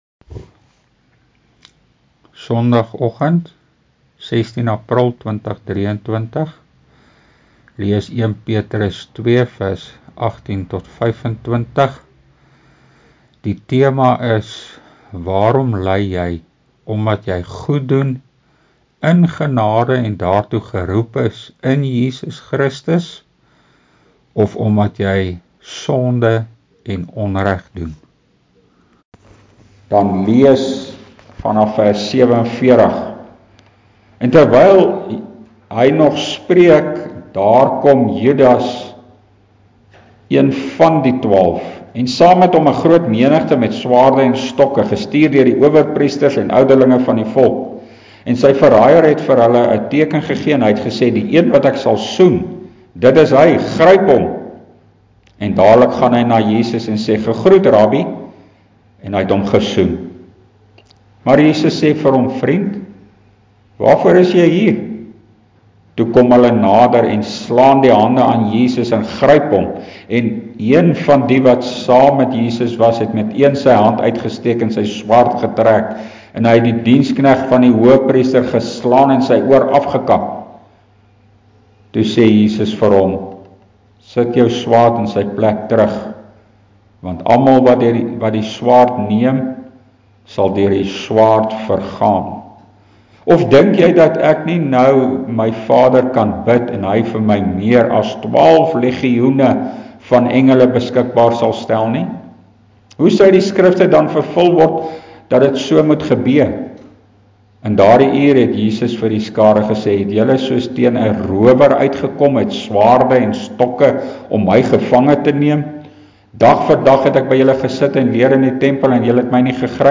1 PETRUS 2 PREKE: (13)